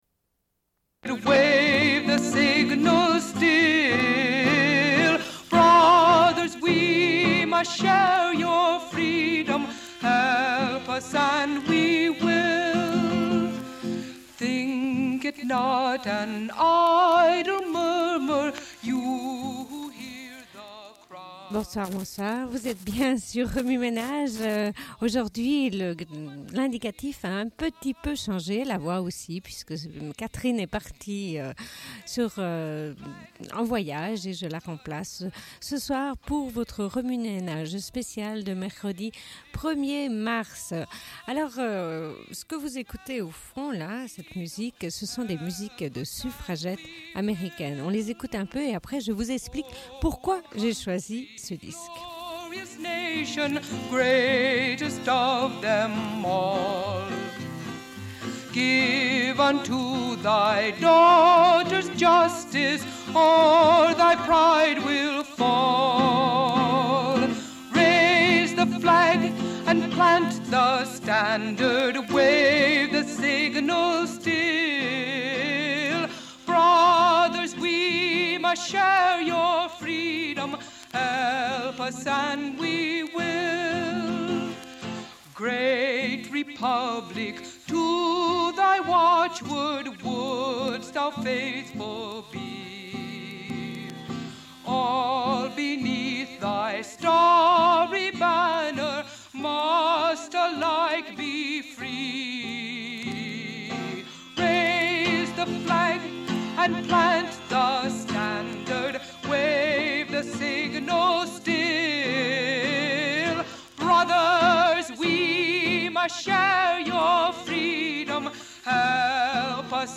Une cassette audio, face A39:00